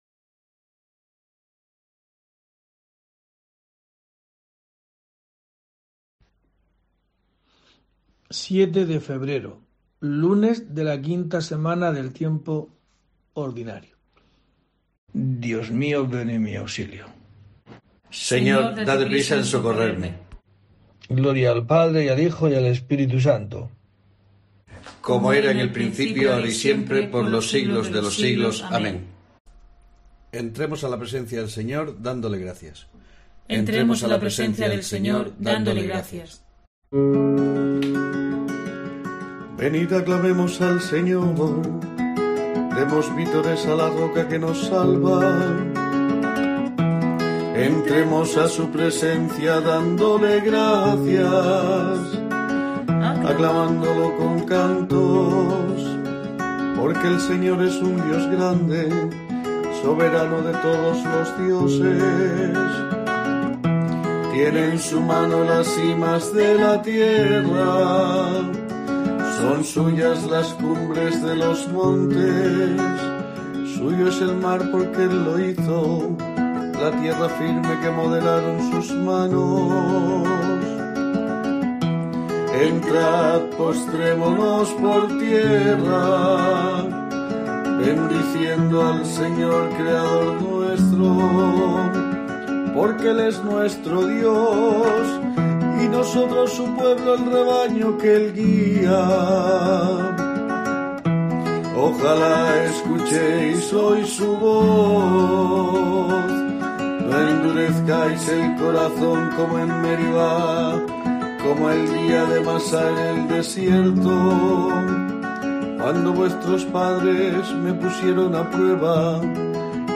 07 de febrero: COPE te trae el rezo diario de los Laudes para acompañarte